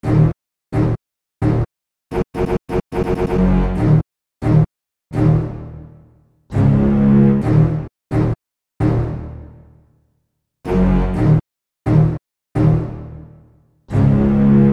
Bass 09.wav